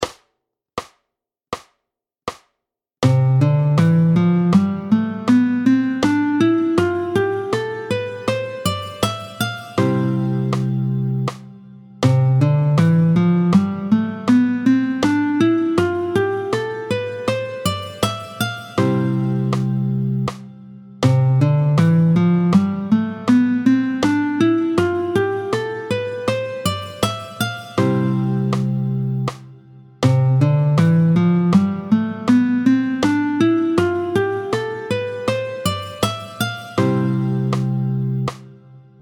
Le mode (et le doigté I) : do ré mi fa sol la si do, est appelé le Ionien.
27-01 Le doigté du mode de Do ionien, tempo 80
27-01-Do-ionien.mp3